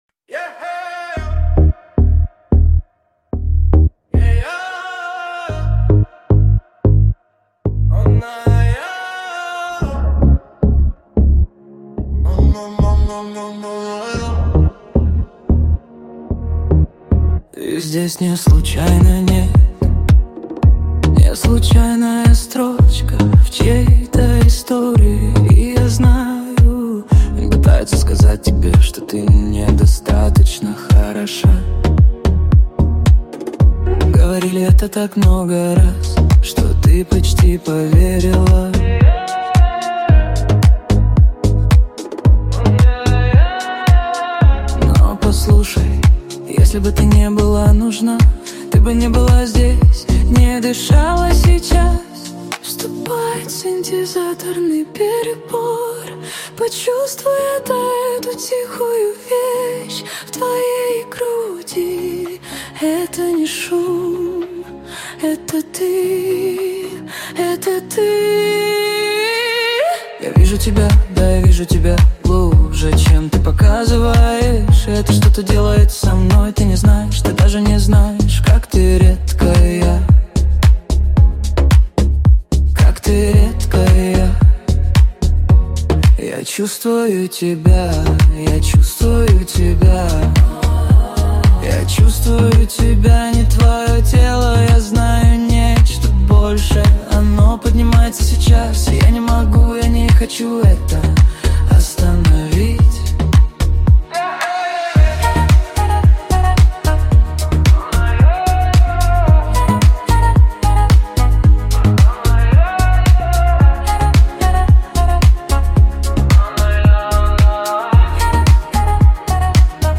Вступает синтезаторный перепад